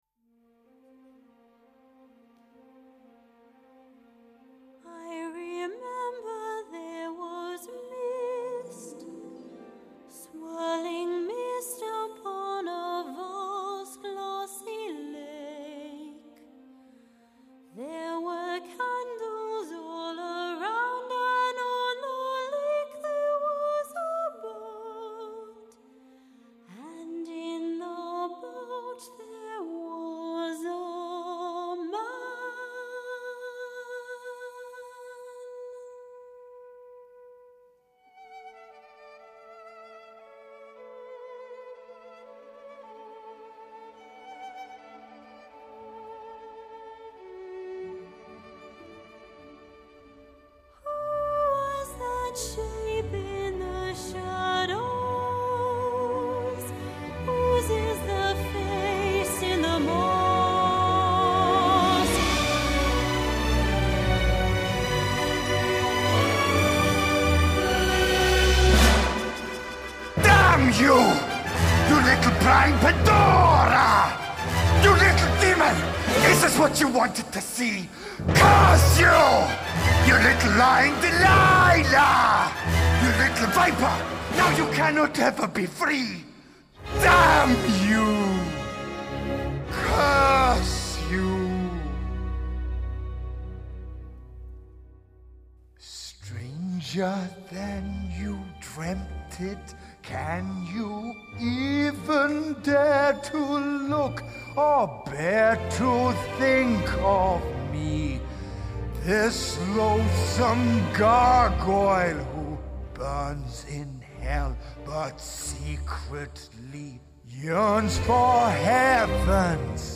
音乐类型：电影配乐